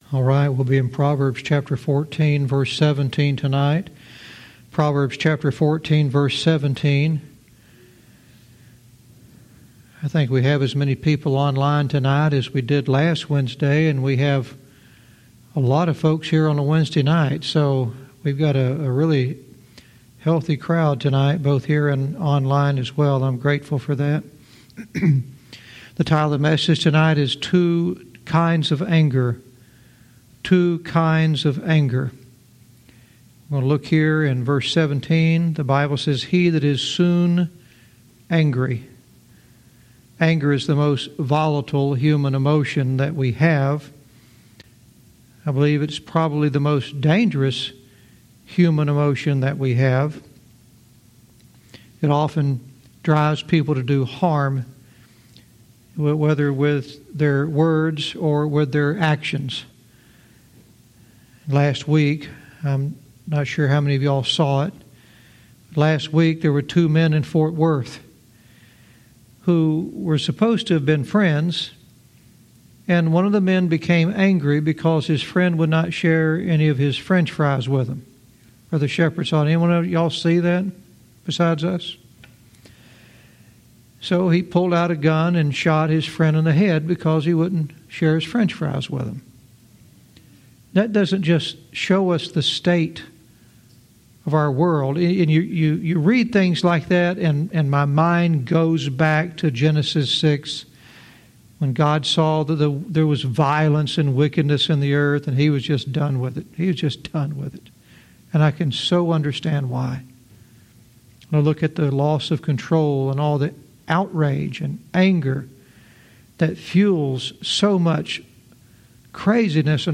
Verse by verse teaching - Proverbs 14:17 "Two Kinds of Anger"